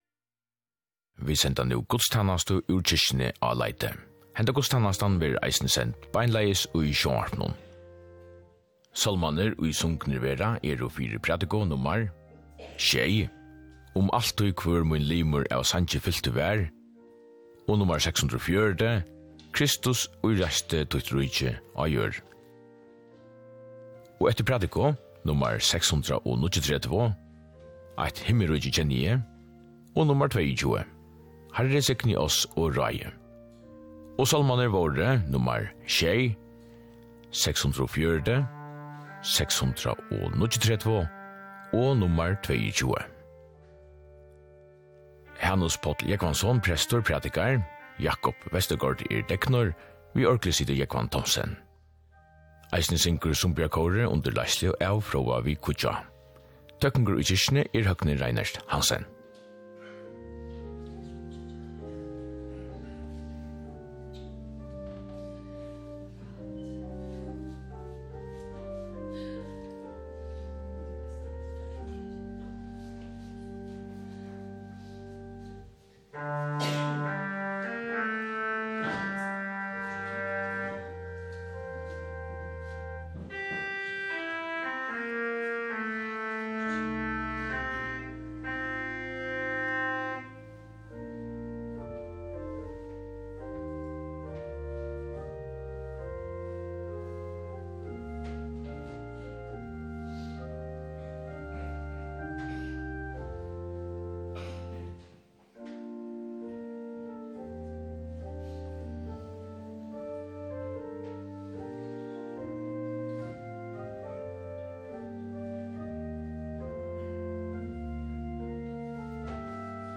Guðstænasta í kirkjuni á Leiti.
Útvarpið sendir beinleiðis gudstænastu hvønn sunnudag í árinum úr føroysku fólkakirkjuni.
Sagt verður, hvør er prestur, deknur, urguleikari og klokkari, og hvørjir sálmar verða sungnir.